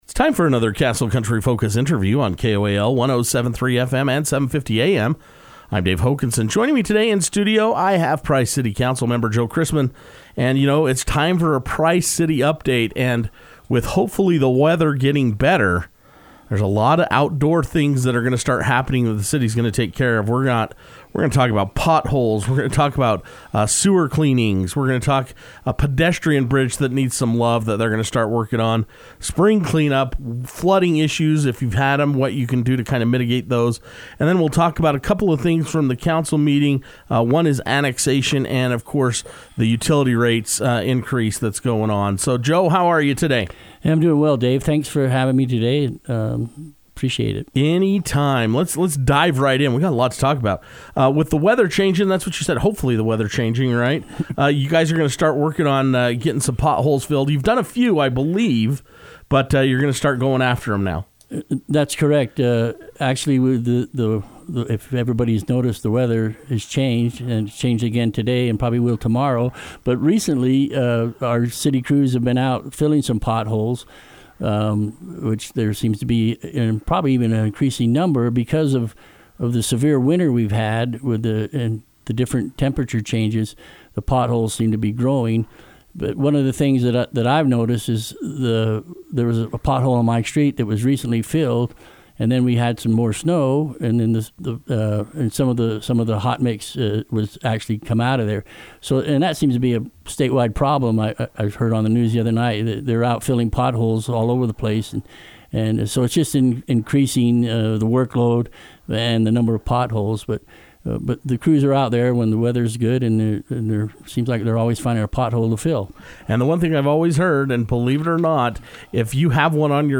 It’s time for another Price City update and Castle Country Radio was able to sit down with City Council Member Joe Christman who spoke about potholes, pedestrian bridge, and spring cleanup.